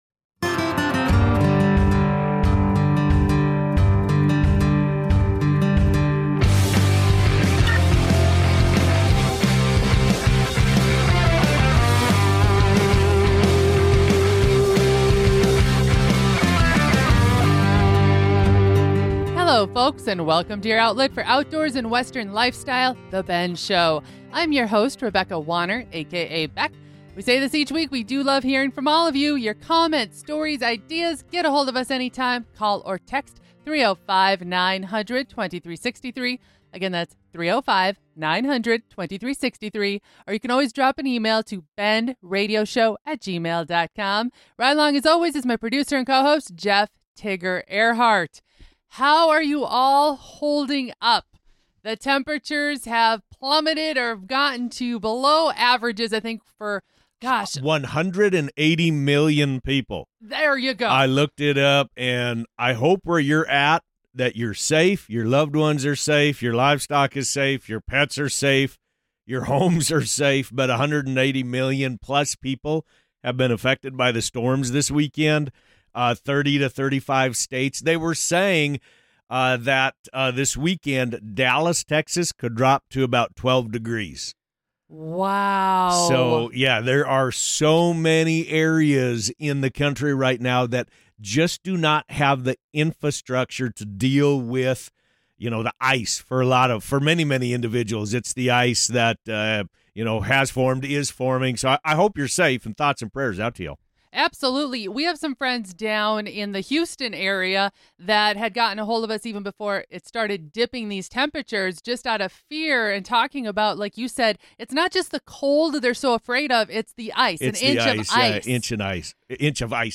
TRIM Radio The Bend Radio Show